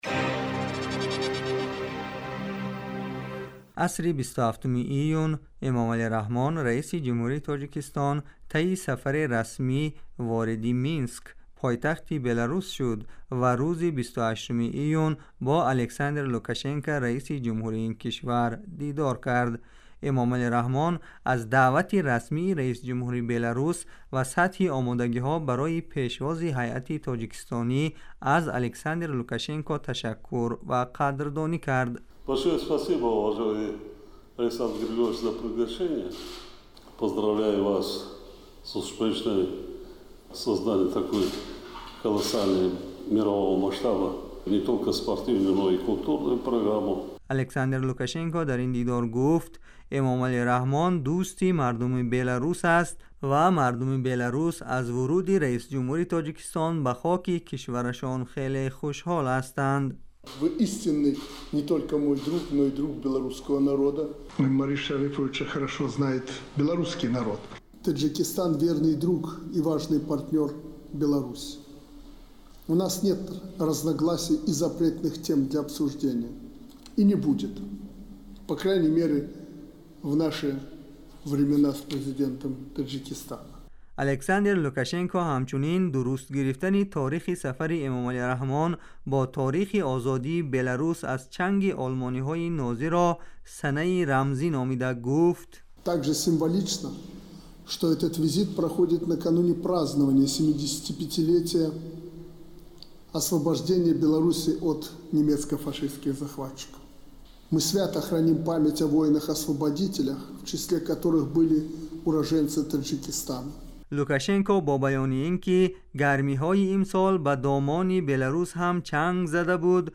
گزارش ویژه : بررسی سفر امامعلی رحمان به بلاروس